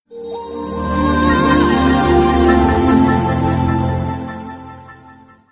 Нарезка на смс или будильник